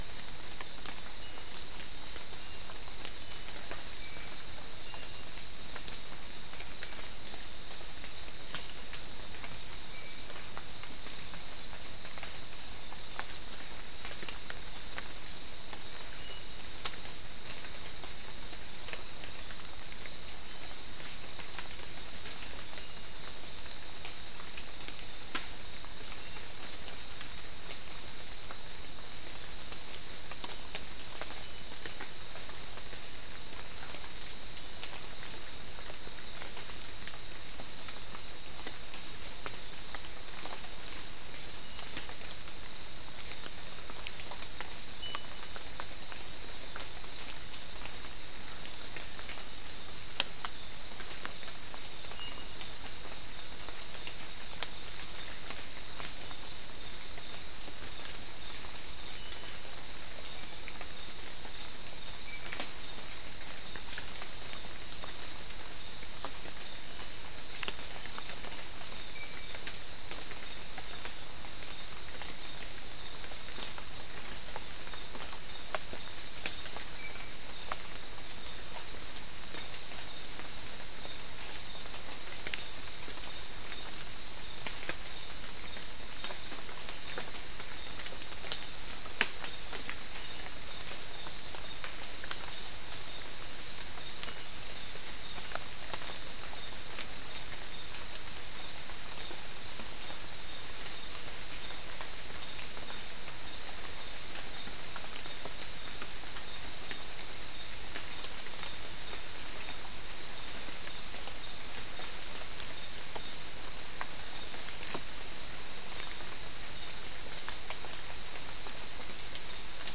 Soundscape Recording Location: South America: Guyana: Kabocalli: 4
Recorder: SM3